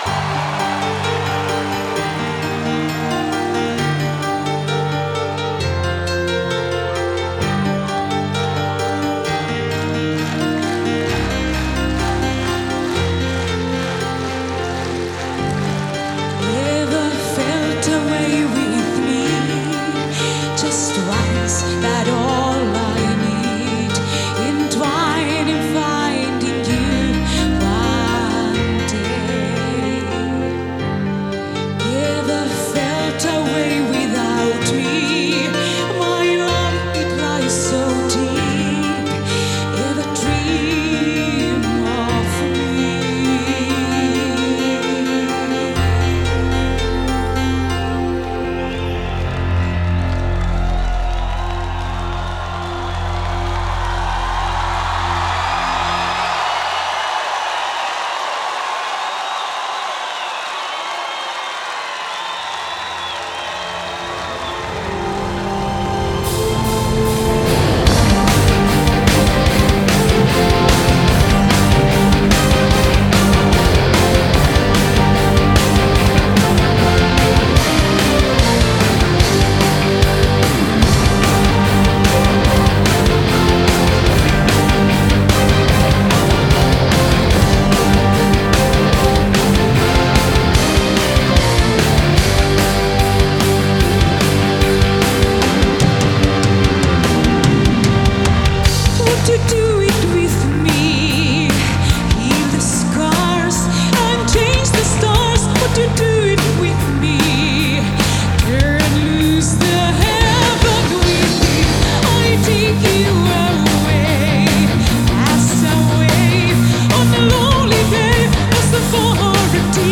Genre: Symphonic Rock